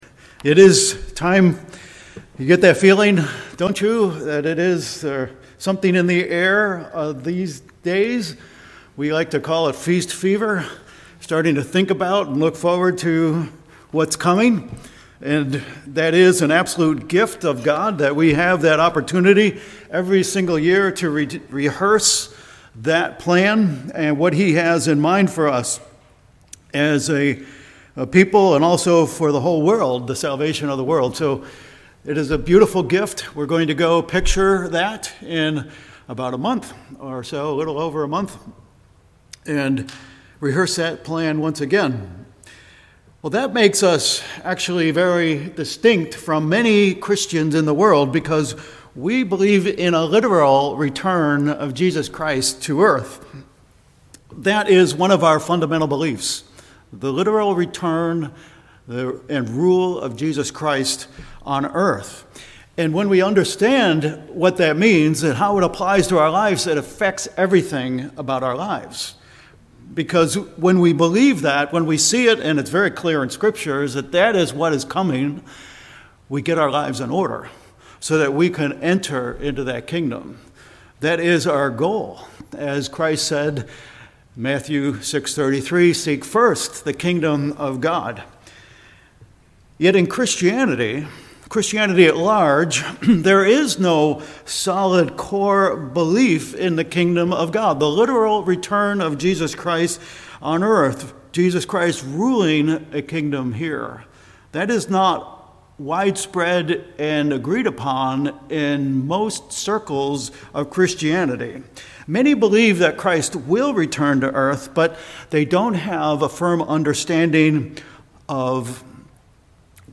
Sermons
Given in Vero Beach, FL Ft. Myers, FL